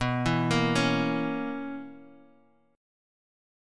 BM7sus2sus4 chord